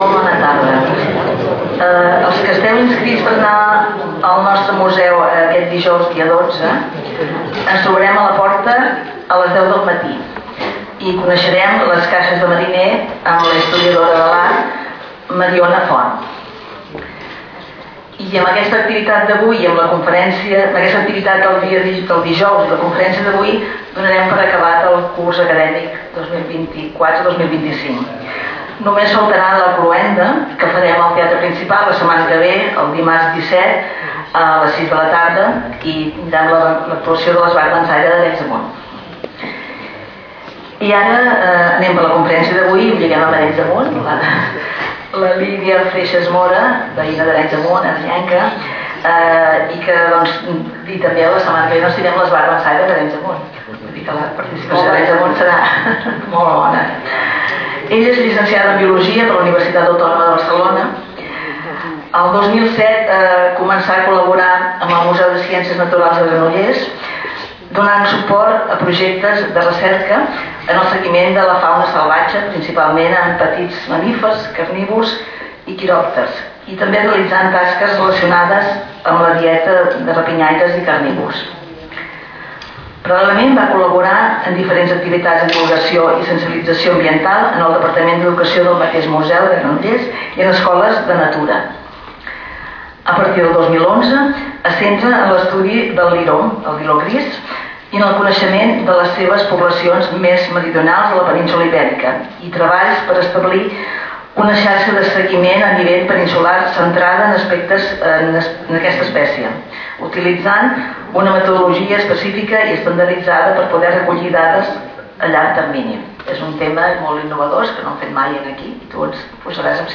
Lloc: Sala d'actes del Col.legi La Presentació
Conferències